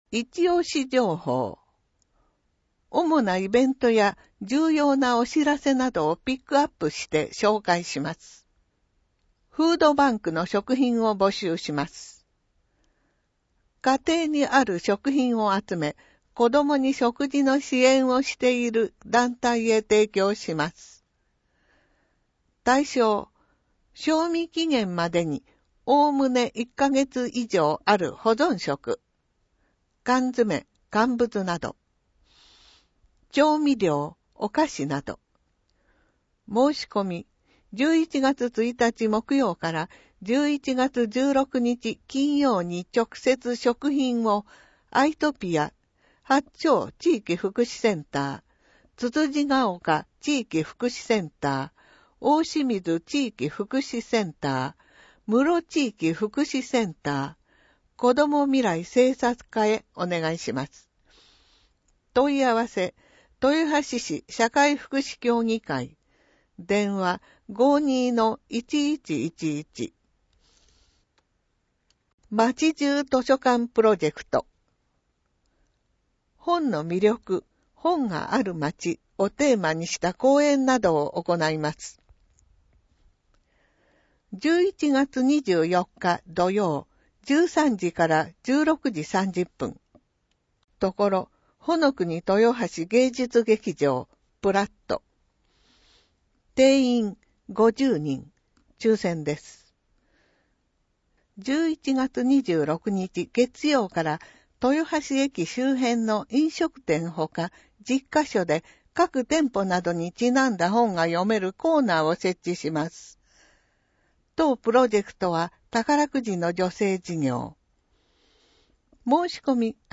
• 「広報とよはし」から一部の記事を音声でご案内しています。視覚障害者向けに一部読み替えています。
（音声ファイルは『音訳グループぴっち』提供）